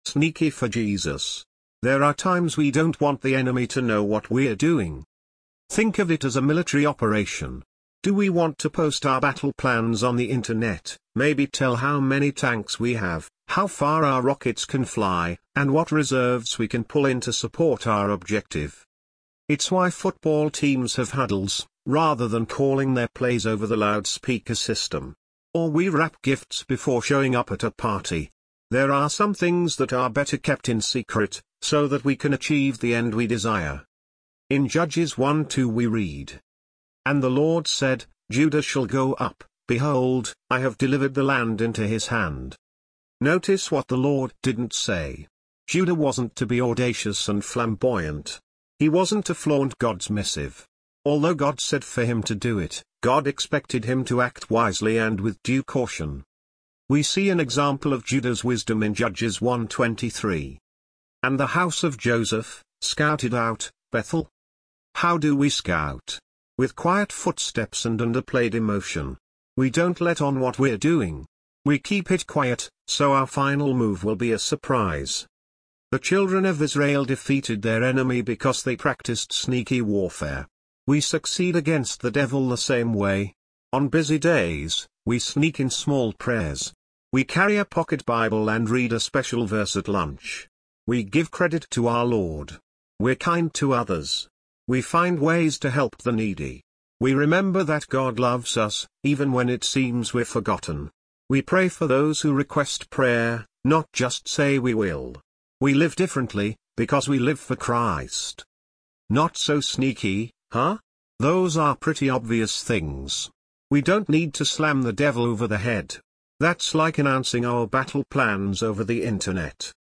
English Audio Version